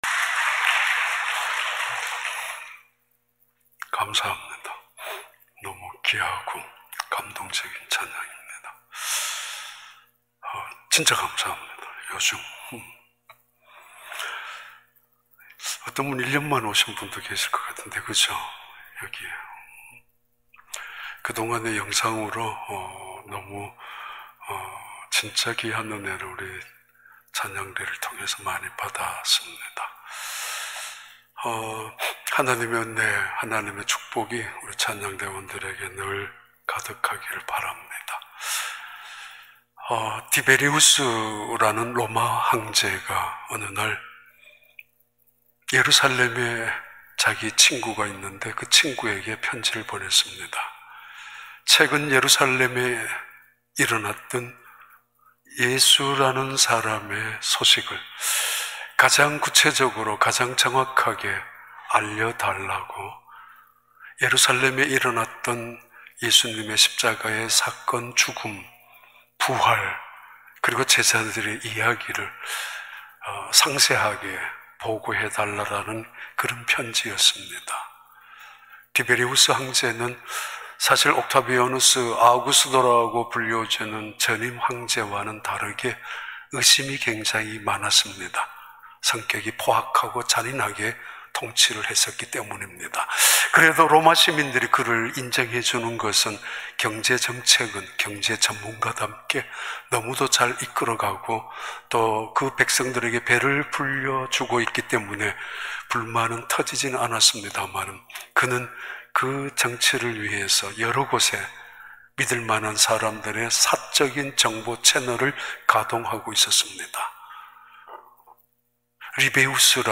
2021년 4월 4일 주일 4부 예배
부활 주일 4부 예배